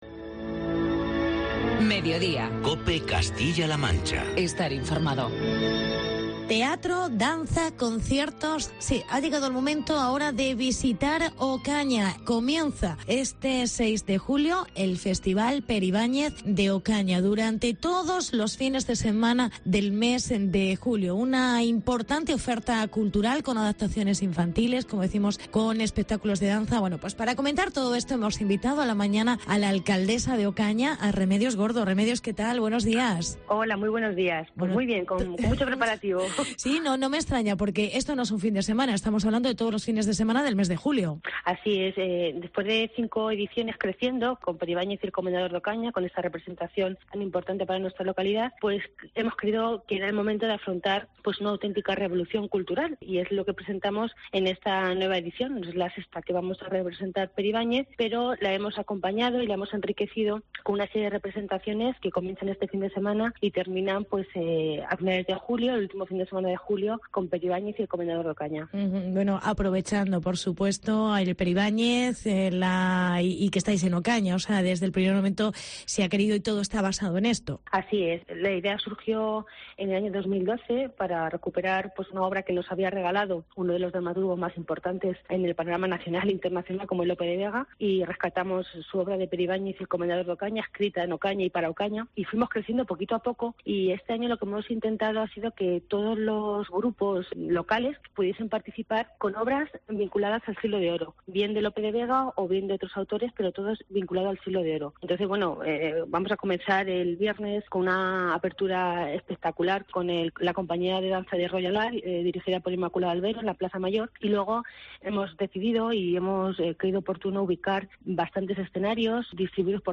Entrevista con Remedios Gordo. Alcaldesa de Ocaña